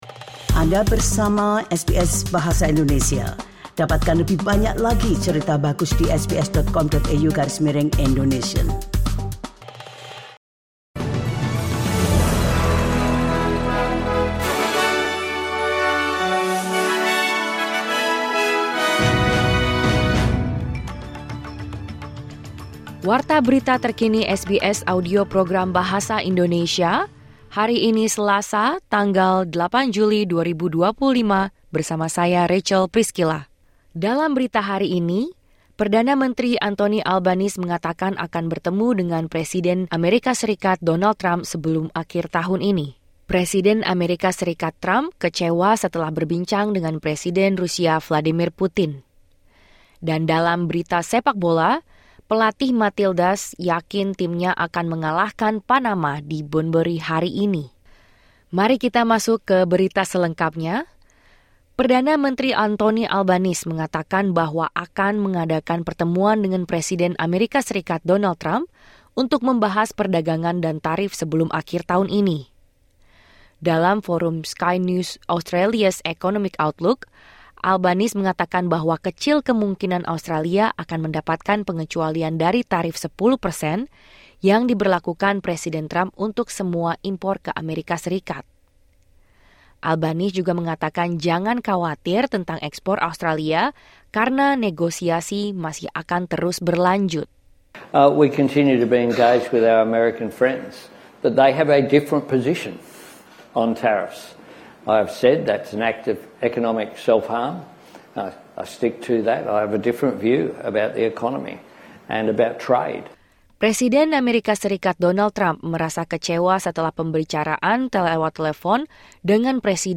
Berita Terkini SBS Audio Program Bahasa Indonesia